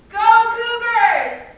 Prosodic Patterns in English Conversation
audio examples for Chapter 7: Expressing Positive Assessment